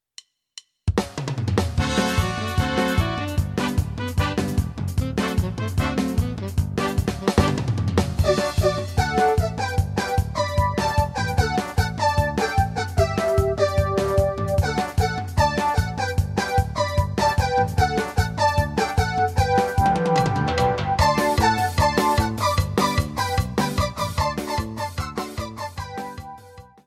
25-Qbradita.mp3